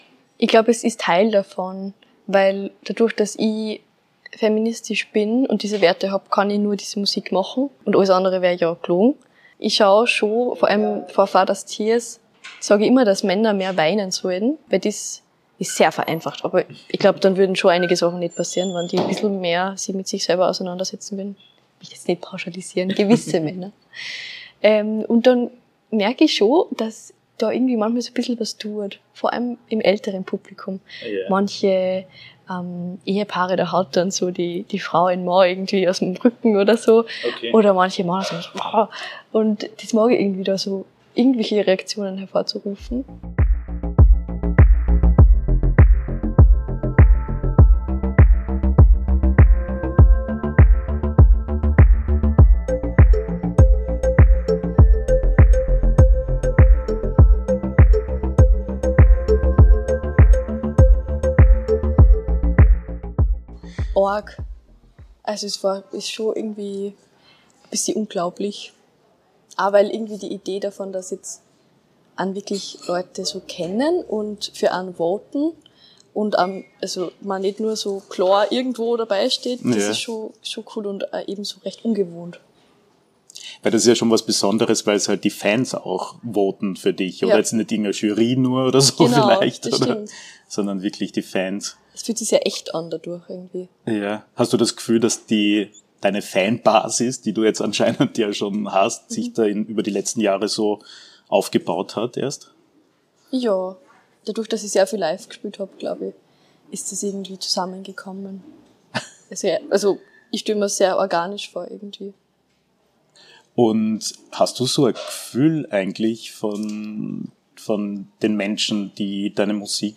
Im Wiener Café Weidinger erzählt sie von ihrem Weg zur Musik.